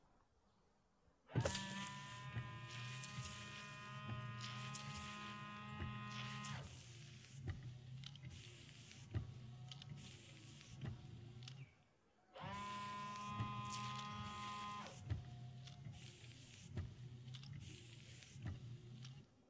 挡风玻璃雨刮器 - 声音 - 淘声网 - 免费音效素材资源|视频游戏配乐下载
汽车挡风玻璃刮水器移动和喷洒水。